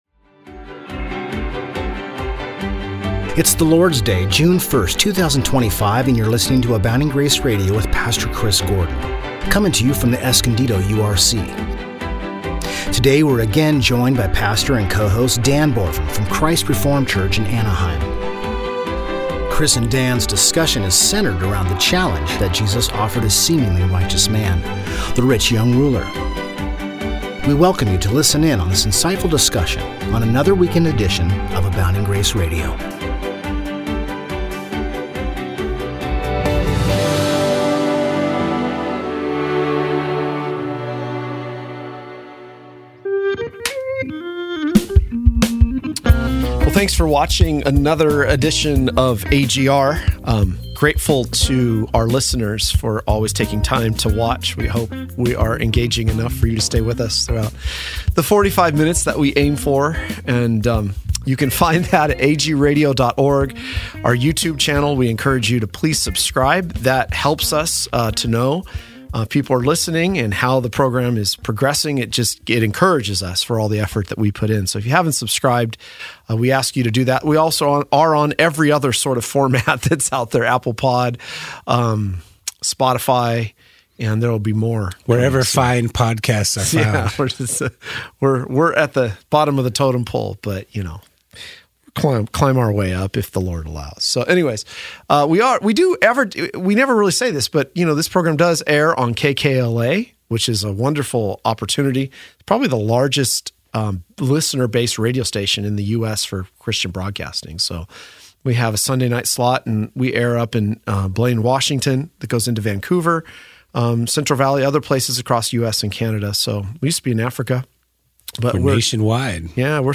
This discussion explores the interplay of law and gospel, the three uses of the law in reformed theology, and the surprising challenge Jesus poses to a seemingly righteous man.